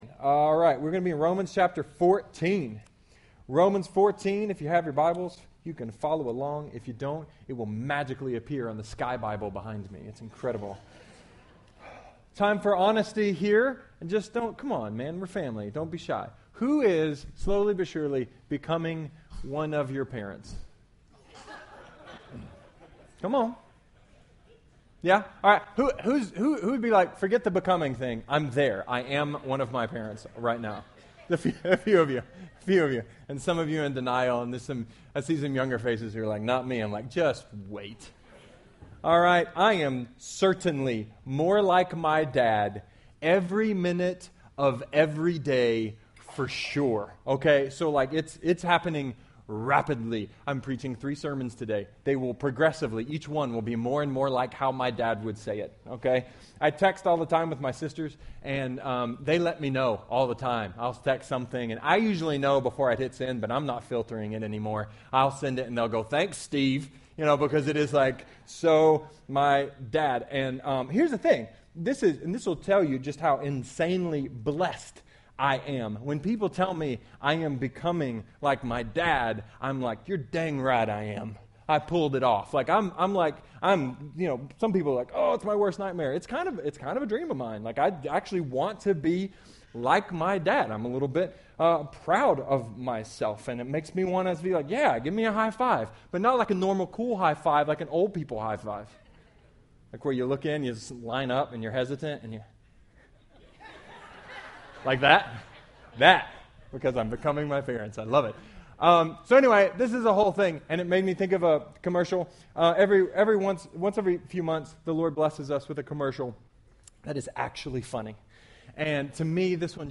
A sermon about eating meat, drinking alcohol, and growing up.